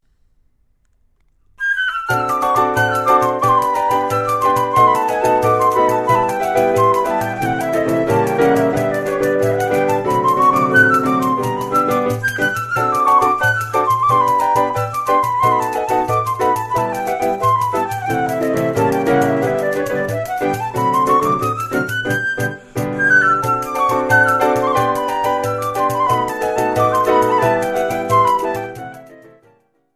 The first edition of this polka
Full stereo track: piano, flute and pandeiro   US$ 1.45